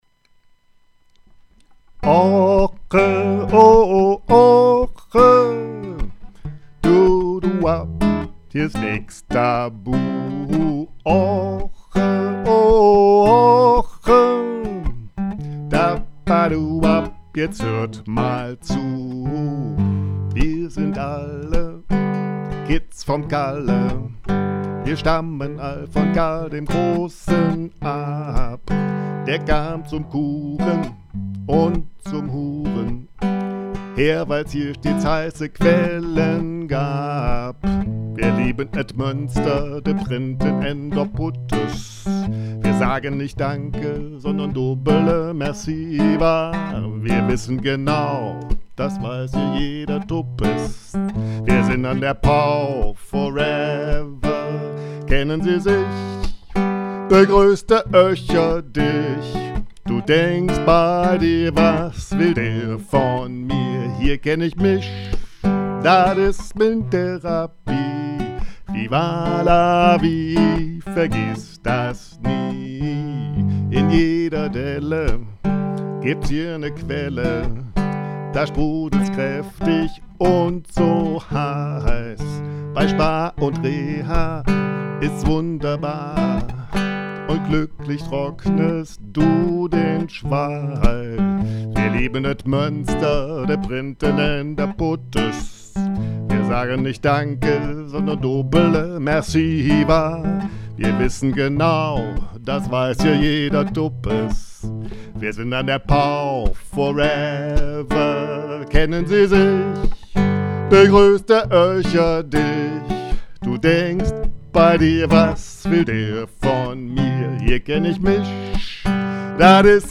Ich habe das Lied mal provisorisch aufgenommen.